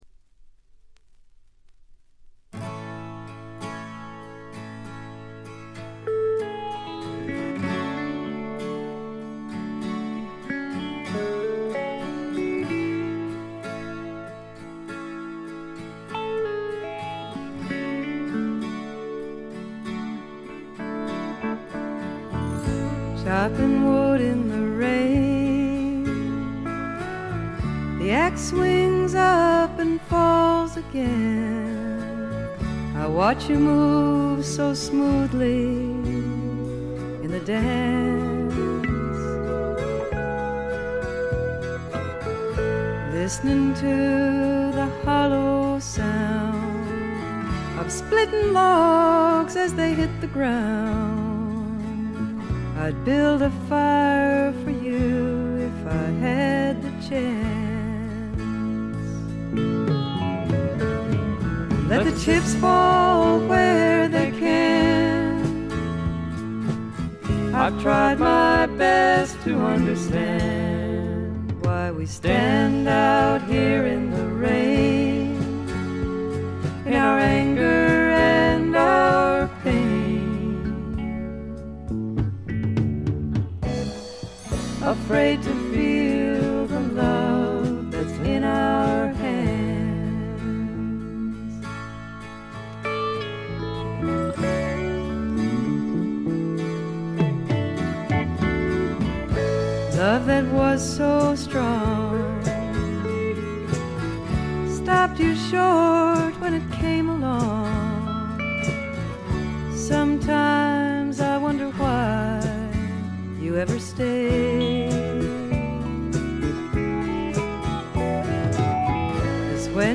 ほとんどノイズ感無し。
本作もしみじみとした歌の数々が胸を打つ女性フォーキー・シンガーソングライターの基本です。
試聴曲は現品からの取り込み音源です。